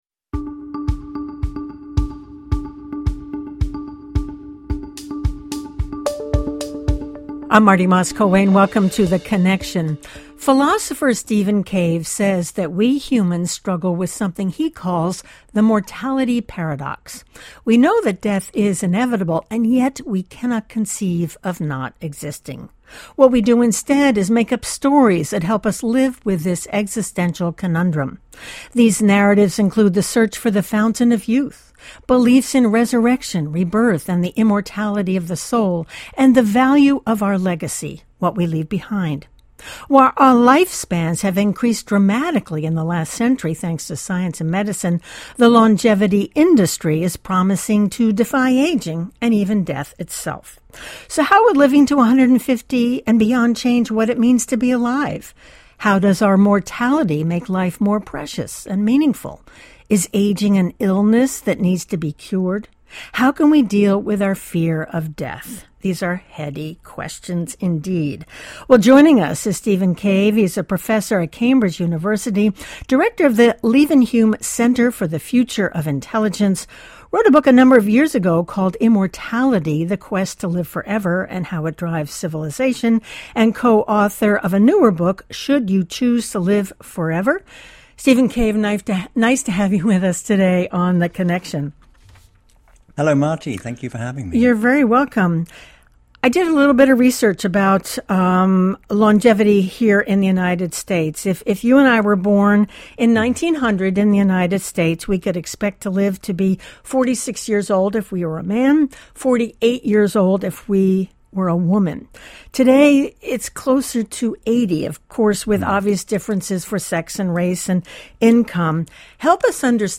Thoughtful discussions